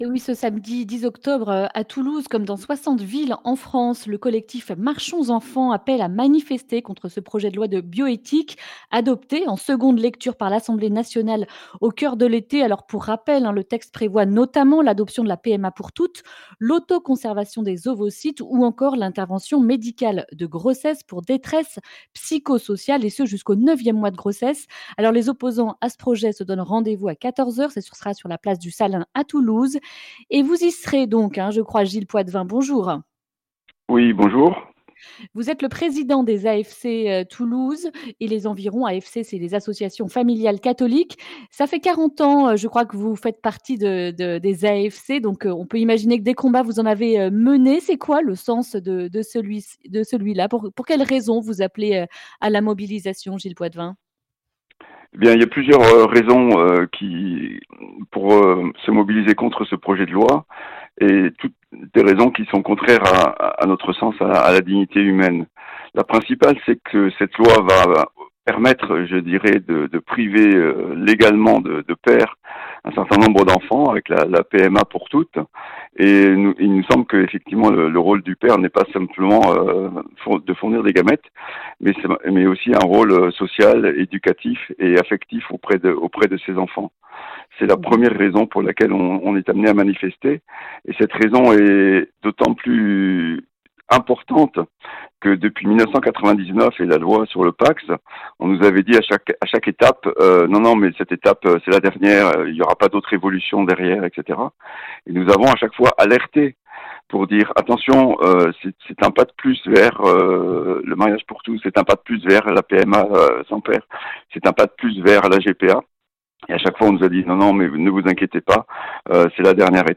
jeudi 8 octobre 2020 Le grand entretien Durée 10 min